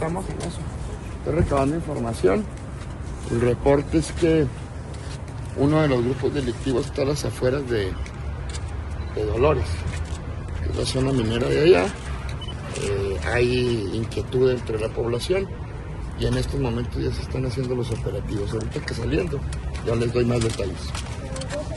Interrogado sobre el panorama de inseguridad, el fiscal general César Jáuregui declaró que las autoridades se encuentran en la fase de recabar información, mientras que los agentes operativos recibieron la instrucción de acudir a Guadalupe y Calvo para disminuir los índices delictivos.
Jauregui-enfrentamiento-.mp3